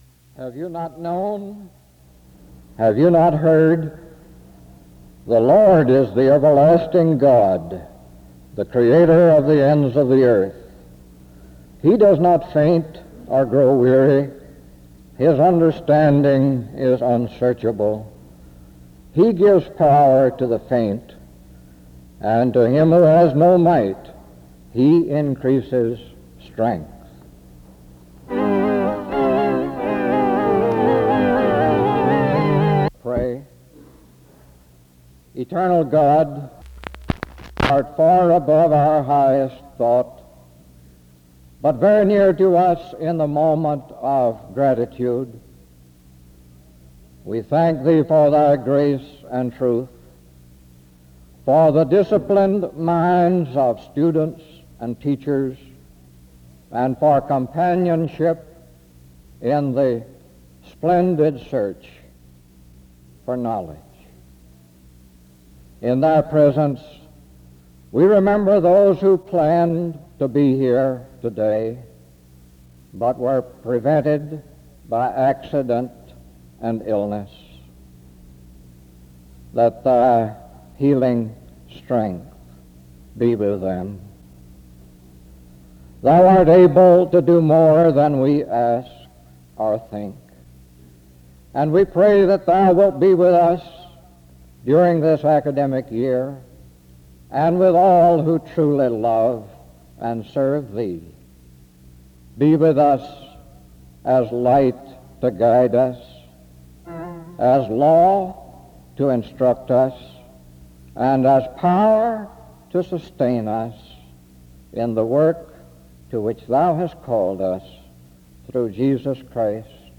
The service begins with an introduction and prayer (0:00-2:03).
The service continues with singing (5:39-10:36).